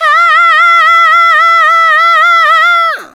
SCREAM 5.wav